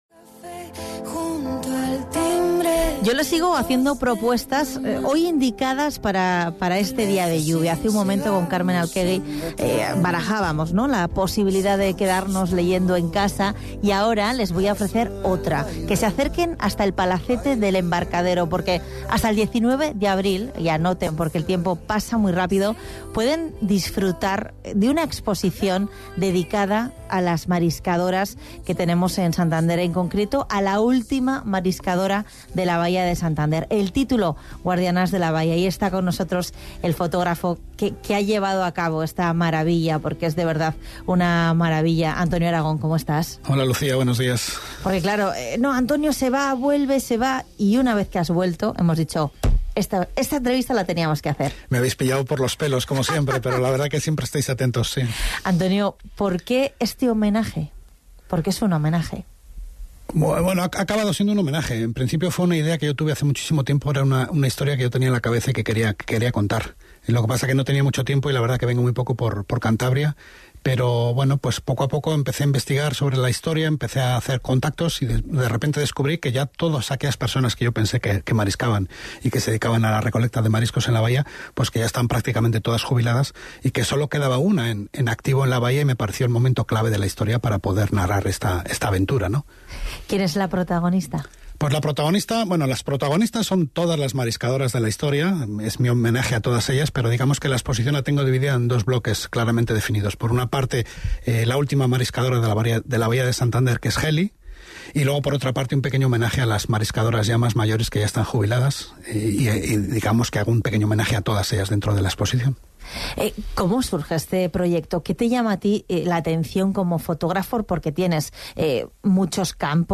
Entrevista en el programa Bahía Cultural de Arco FM